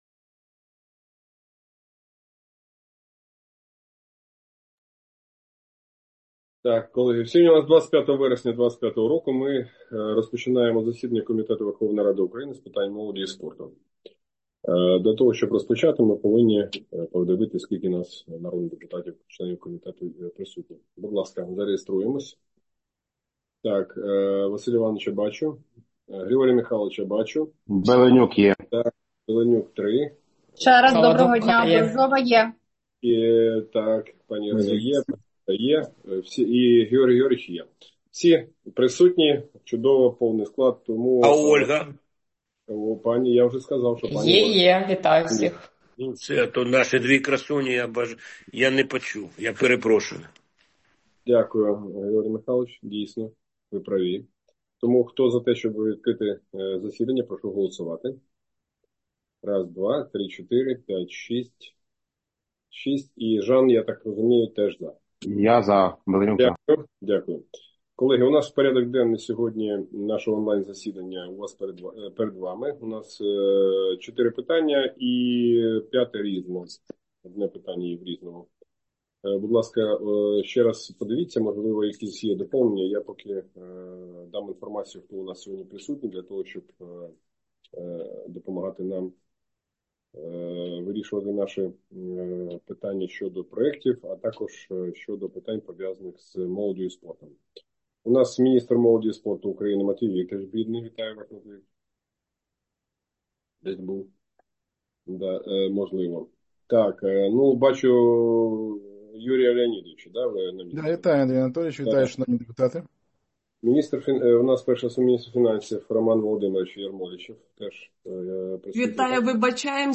Аудіозаписи засідання Комітету у вересні 2025 року